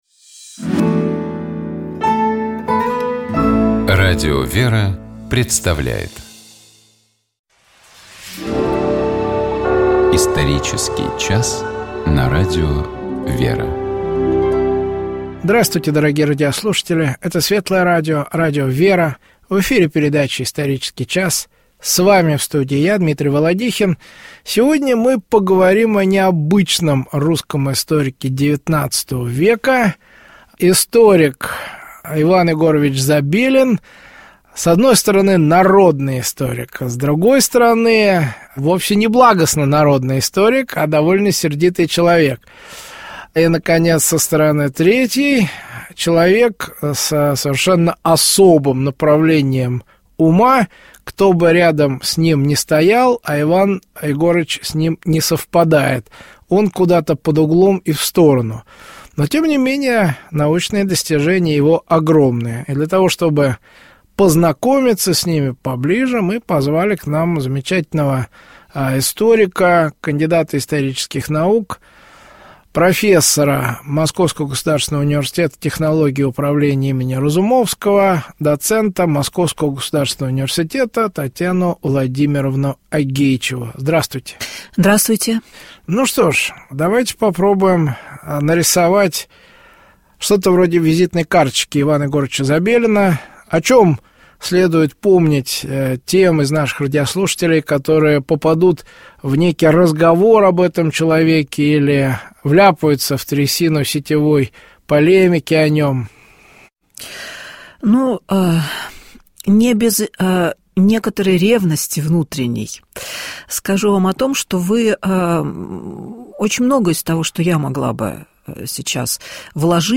Это «Вечер воскресенья» на радио «Вера».